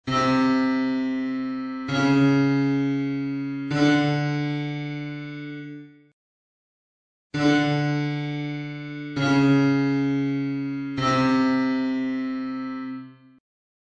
Il diesis innalza il DO di un semitono; ora la distanza tra Do diesis e Re è di un semitono;
Il bemolle abbassa il Re di un semitono; ora la distanza tra Re bemolle e Do è di un semitono;
Vediamolo e ascoltiamolo sulla tastiera di un pianoforte: alterazioni_02.mp3